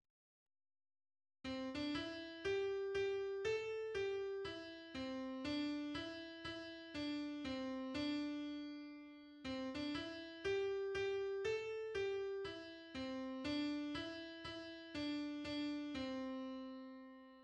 The first two phrases of the melody are based on the major pentatonic scale.[14]
'Oh,_Susanna'_pentatonic_melody.mid.mp3